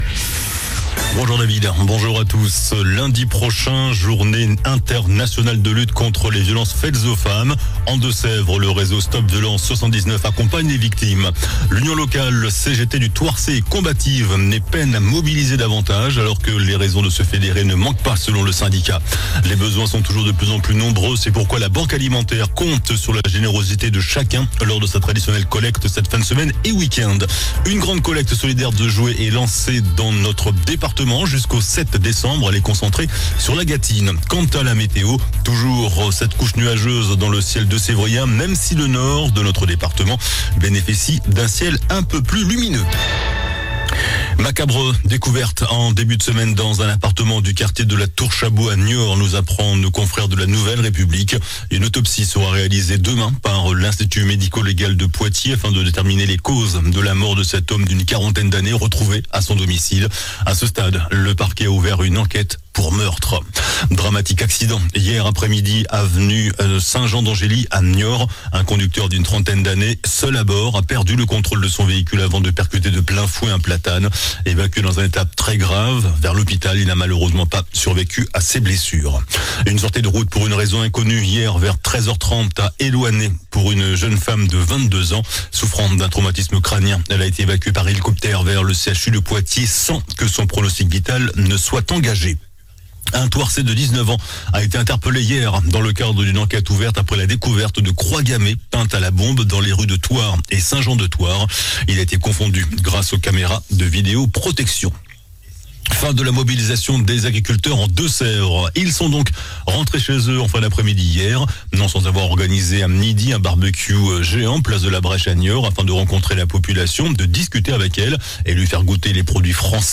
JOURNAL DU MERCREDI 20 NOVEMBRE ( MIDI )